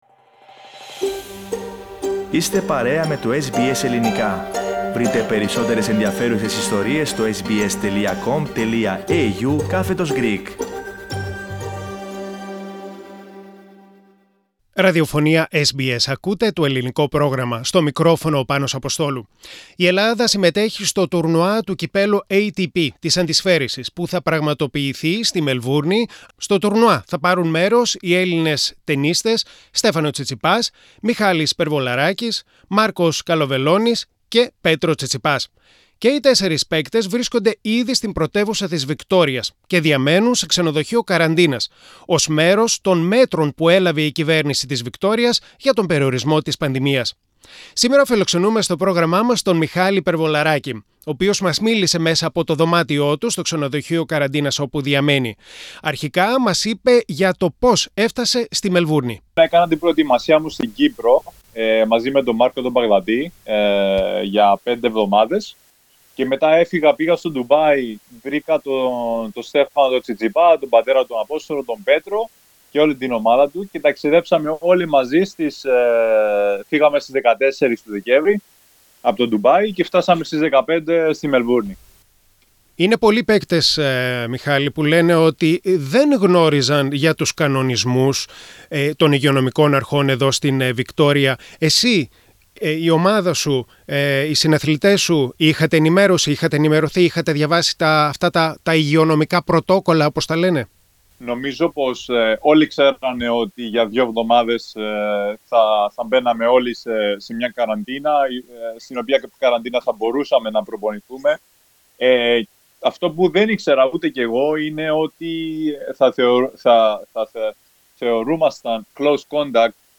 O παίκτης του τένις, Μιχάλης Περβολαράκης συμμετέχει στην Ελληνική ομάδα της αντισφαίρισης που θα πάρει μέρος στο τουρνουά του Κυπέλλου ATP στη Μελβούρνη. Μίλησε στο SBS Greek από δωμάτιο ξενοδοχείου καραντίνας της Μελβούρνης